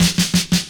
4_Hits_170.wav